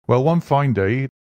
Below are some examples; notice, in the cases where one is preceded by other words, that the pitch steps up onto the word one: